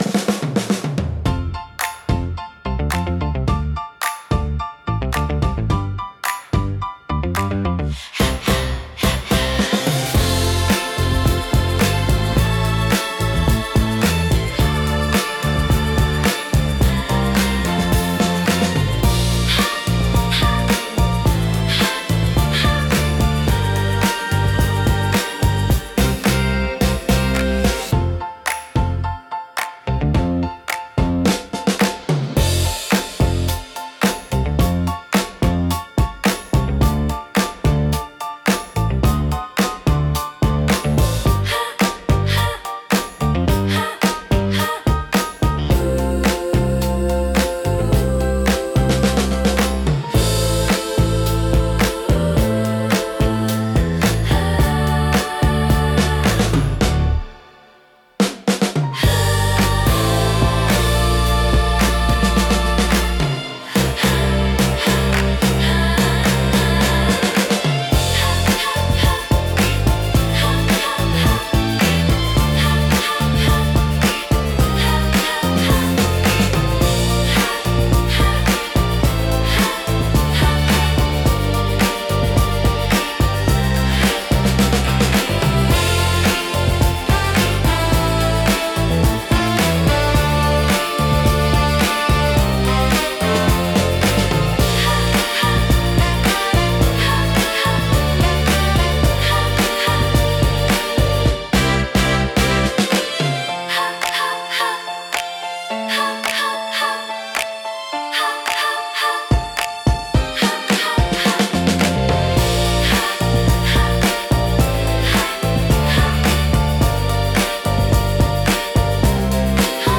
親しみやすいサウンドとポップな雰囲気、明るく感情的な楽曲が多いです。
聴く人の気分を前向きにし、懐かしくも活気ある空間を生み出します。心に残るハーモニーと温かさが魅力のジャンルです。